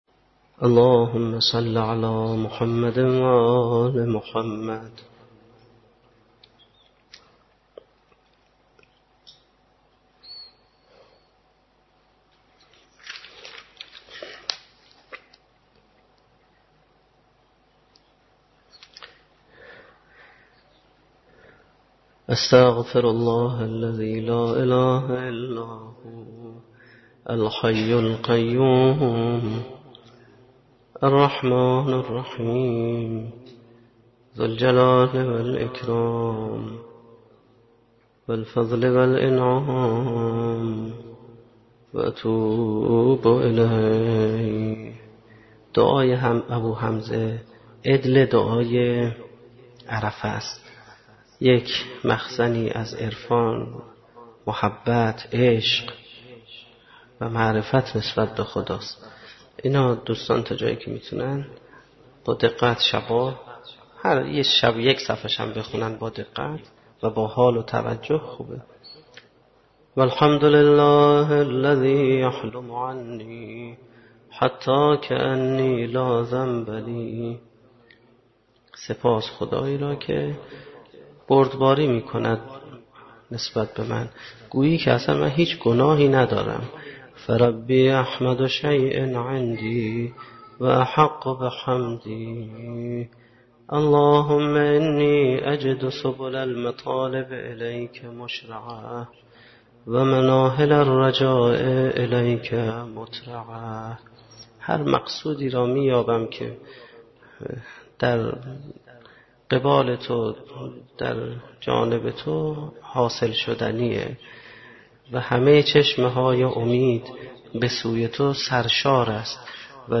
مناجات خوانی